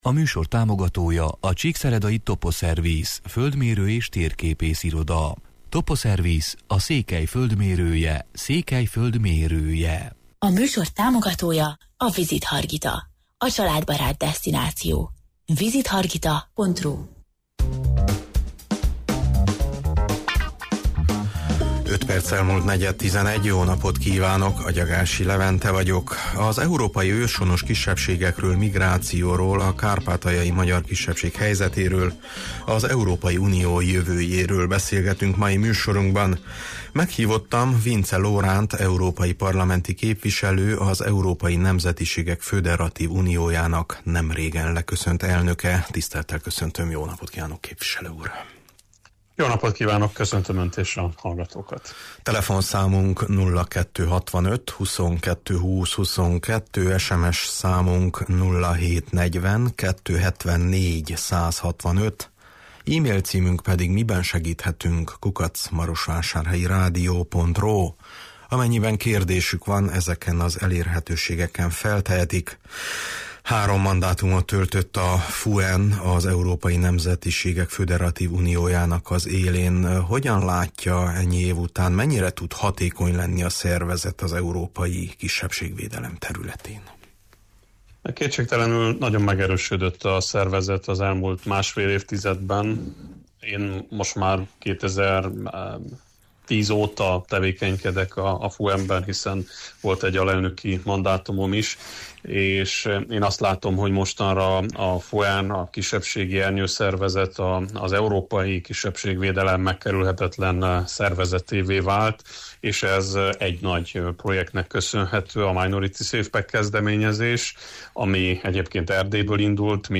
Meghívottam Vincze Lóránt európai parlamenti képviselő, az Európai Nemzetiségek Föderatív Uniójának nemrégen leköszönt elnöke: